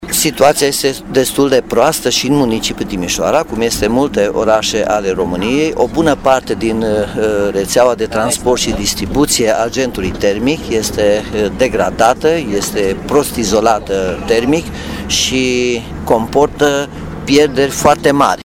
Pierderile de căldură cauzate de starea reţelei de distribuţie reprezintă cele mai mari probleme în municipiul de pe Bega. Primarul Nicolae Robu recunoaşte carenţele şi spune ca ar mai fi mult de lucru la acest capitol.